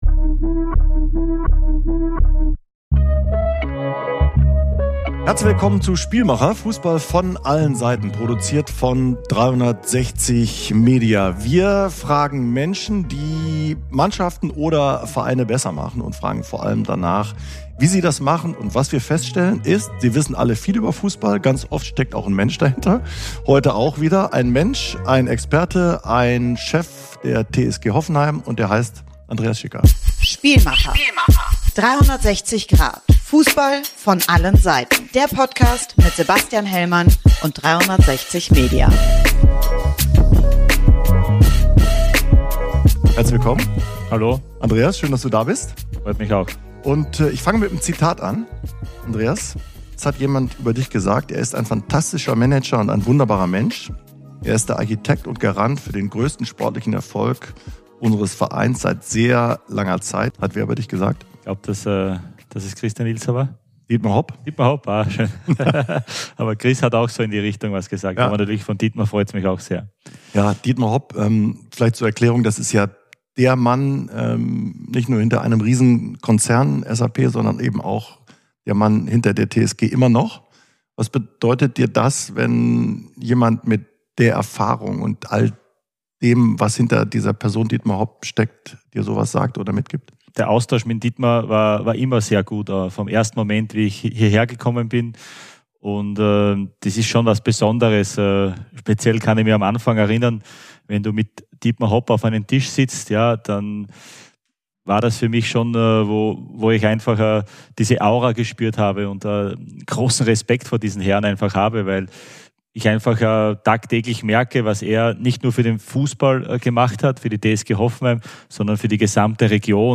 Im Gespräch mit Sebastian Hellmann blickt er auf diese Tage zurück und erzählt, wie wichtig seine enge Bindung zu Trainer Christian Ilzer und der Zuspruch von Mäzen Dietmar Hopp für ihn waren.